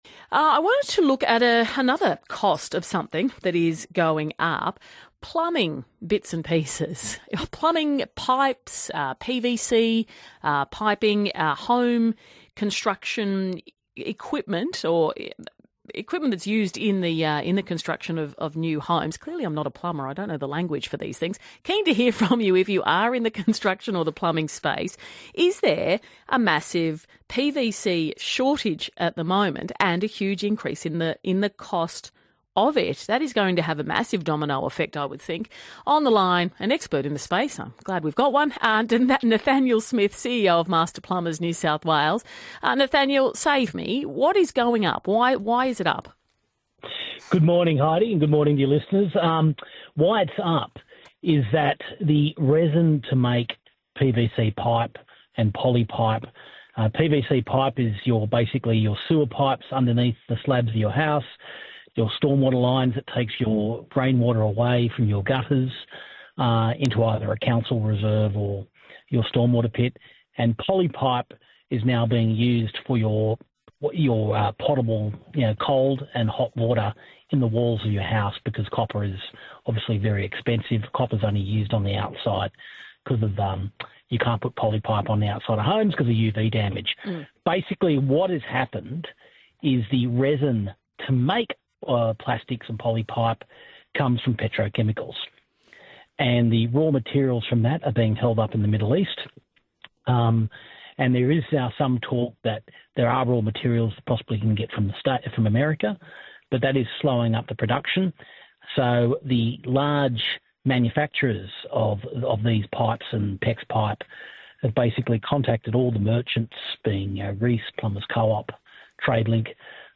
spoke on 3AW Mornings